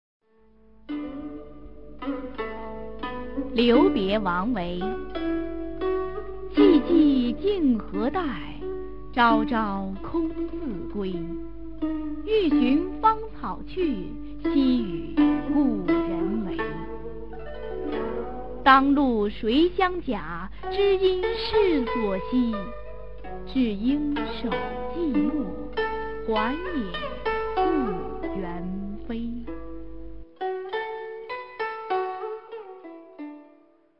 [隋唐诗词诵读]孟浩然-留别王维a 配乐诗朗诵